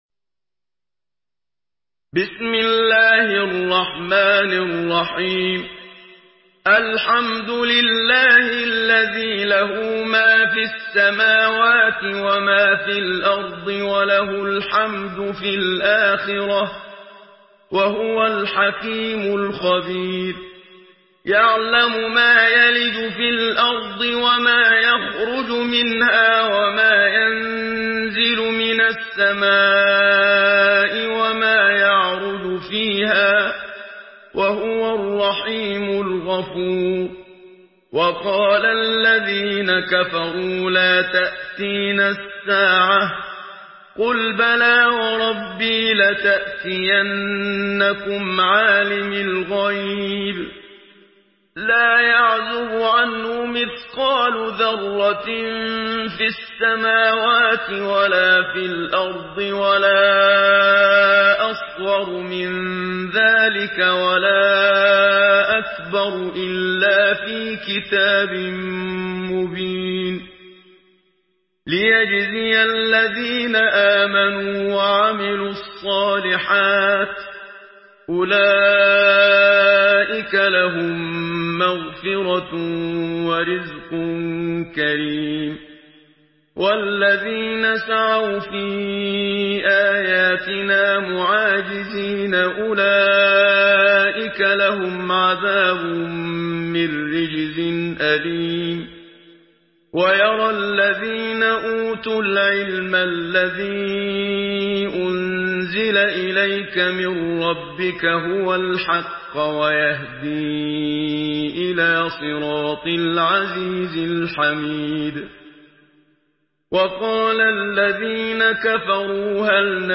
Surah Saba MP3 in the Voice of Muhammad Siddiq Minshawi in Hafs Narration
Murattal Hafs An Asim